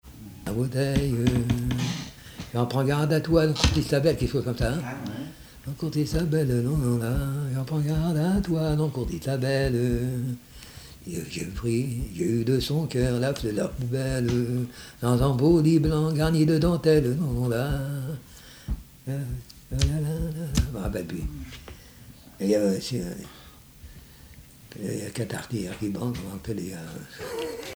Genre laisse
Témoignages sur la pêche et chansons
Pièce musicale inédite